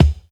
KICK_STRING_REV_2.wav